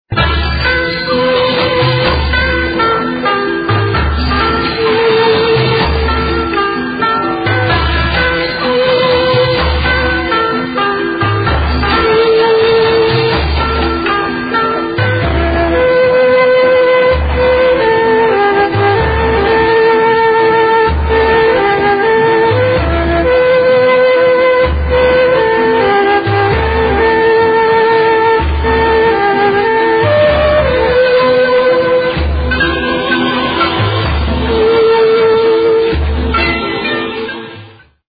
زنگ موبایل
ملودی موبایل